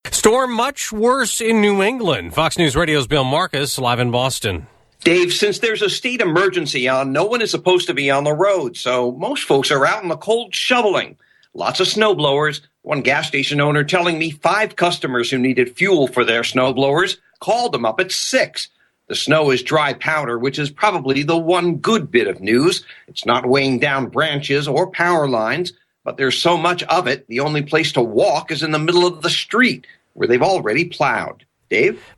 11AM LIVE